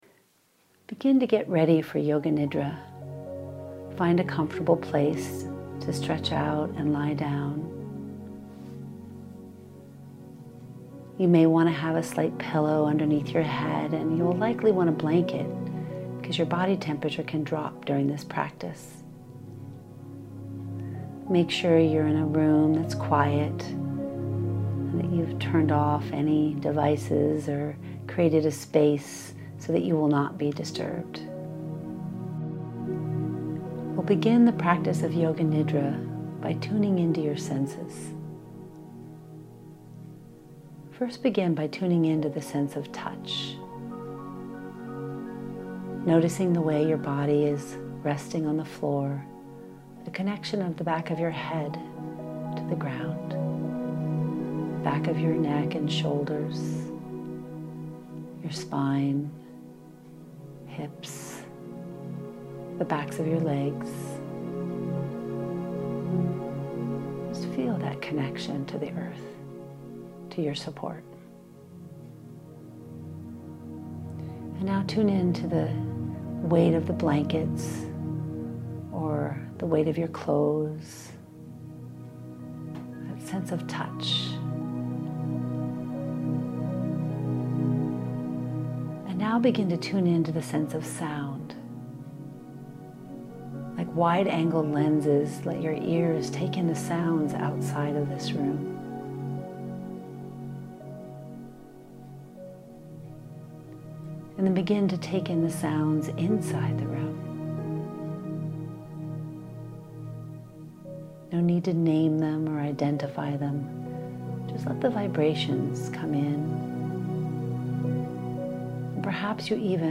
Yoga-Nidra-Meditation-Resource.mp3